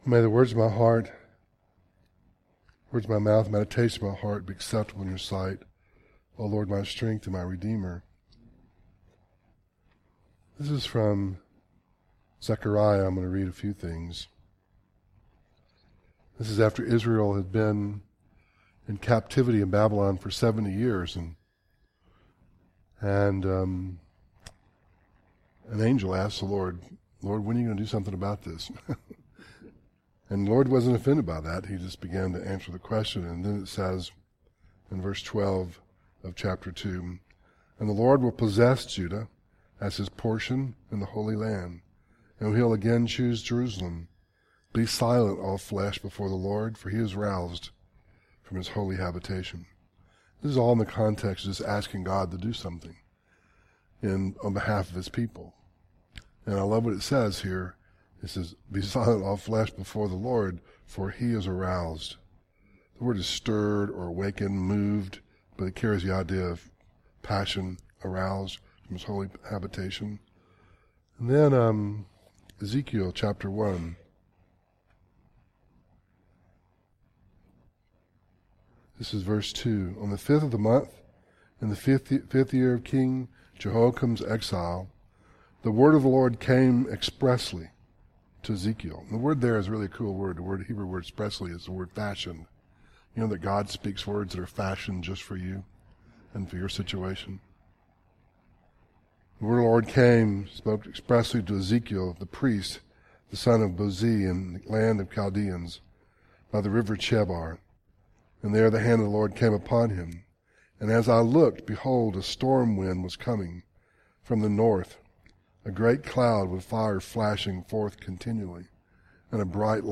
Audio Devotionals Zechariah 2:12-13